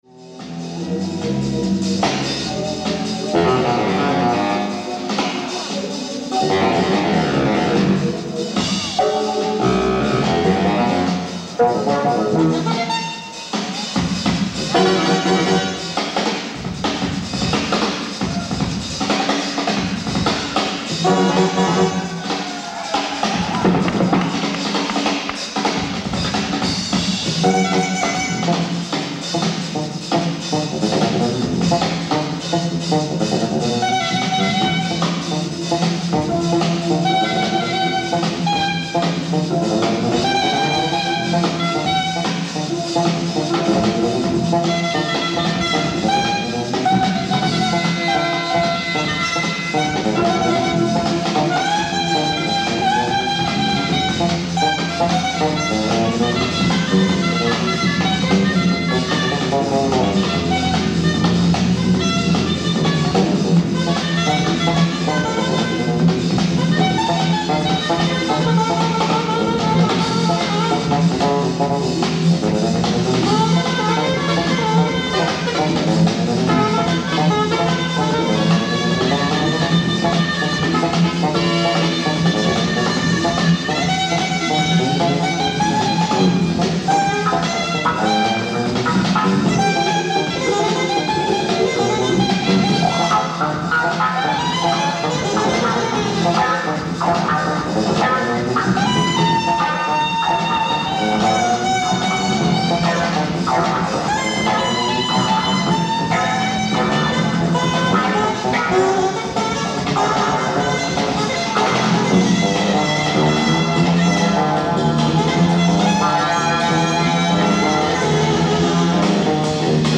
ディスク１＆２：ライブ・アット・ハマースミスオデオン、ロンドン 07/07/1979
※試聴用に実際より音質を落としています。